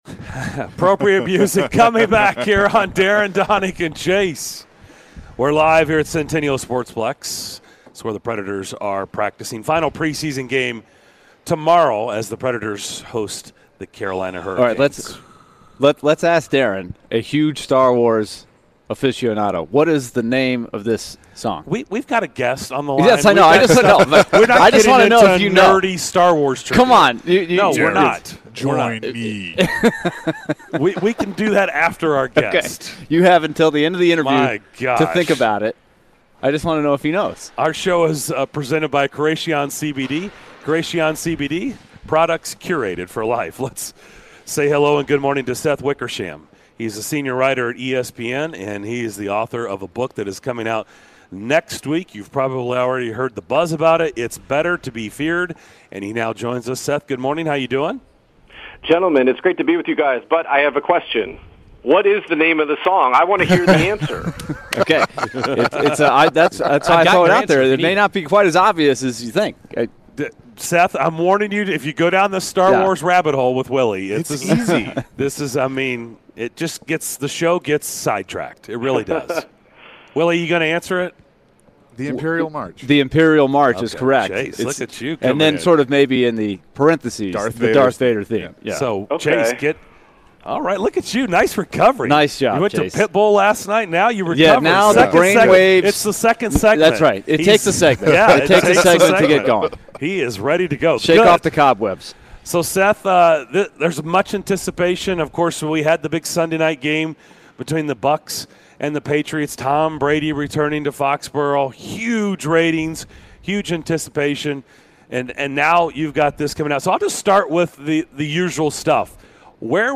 Author of "Better To Be Feared" Seth Wickersham joined the DDC to discuss his book and the breakup of Tom Brady and Bill Belichick.